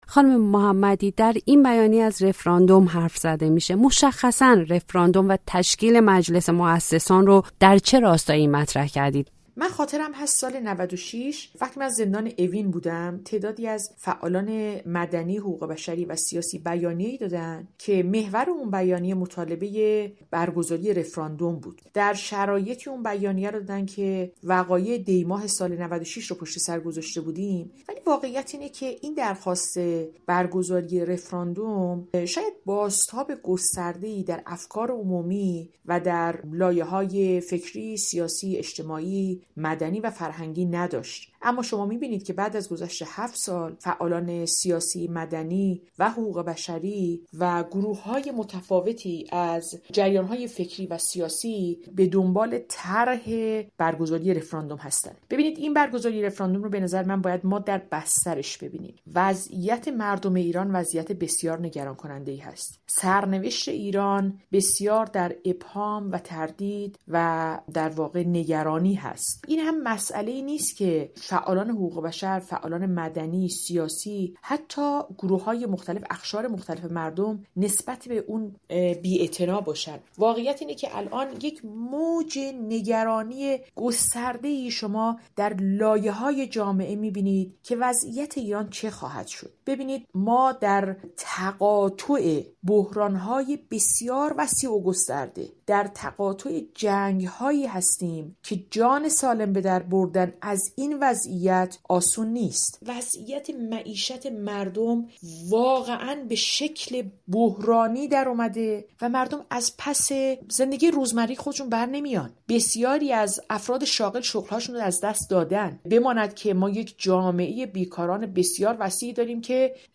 ‌رفراندوم در گفت‌وگو با نرگس محمدی: جمهوری اسلامی، استبدادی و اصلاح‌ناپذیر است